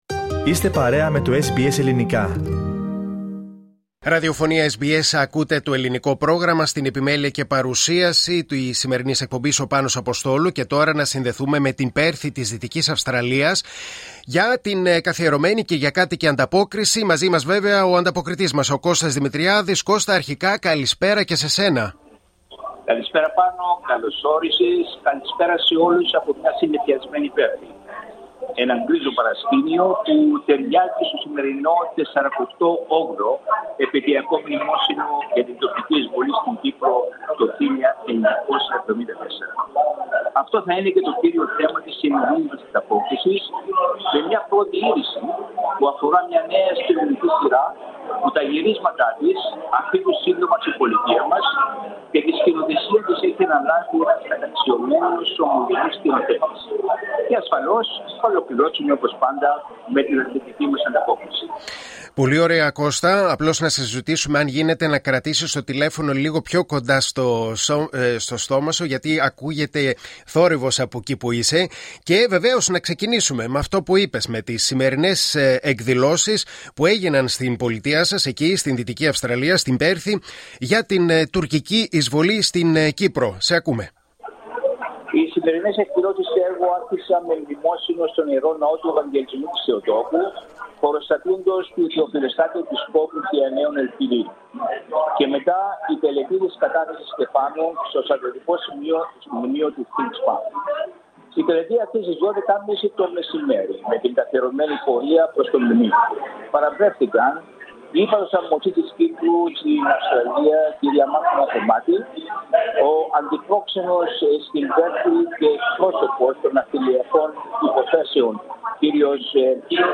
Η εβδομαδιαία ανταπόκριση από την Πέρθη της Δυτικής Αυστραλίας.